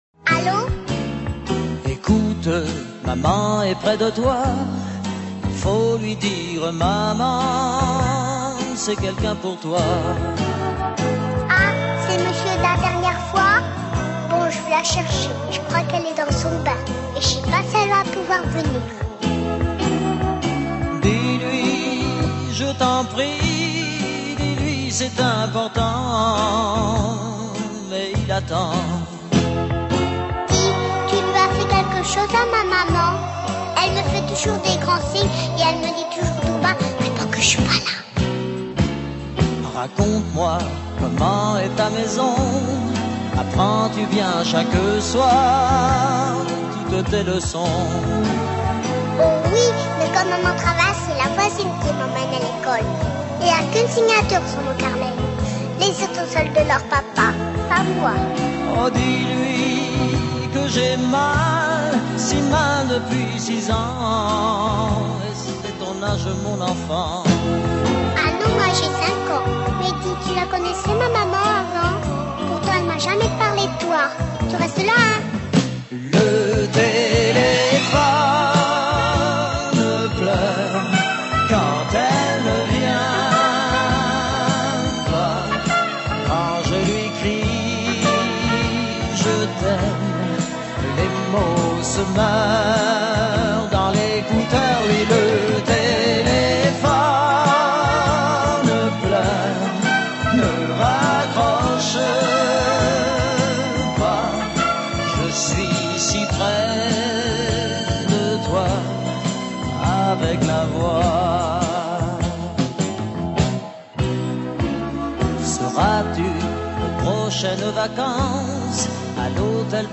И вторая - дуэт с какой-то девочкой.